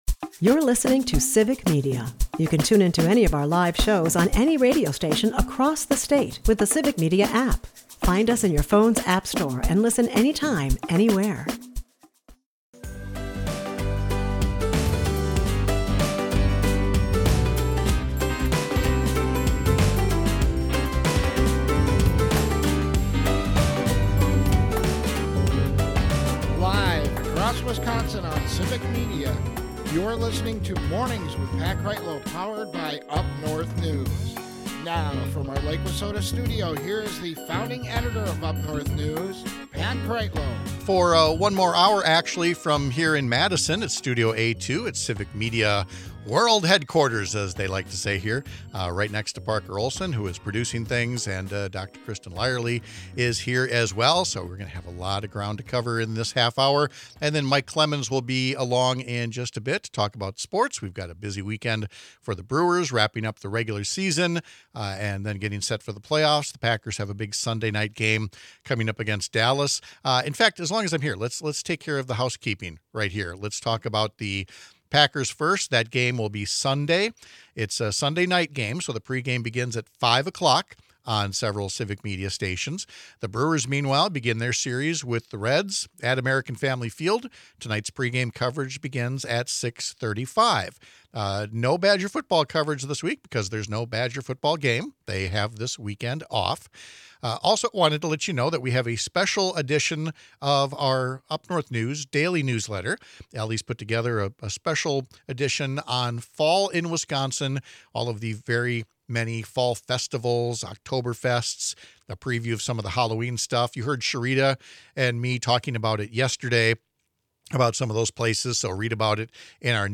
We’ll ask an actual OB-GYN if the blowback has been enough — or if the non-doctors at the White House have sown medical misinformation that’s going to take a long time to debunk.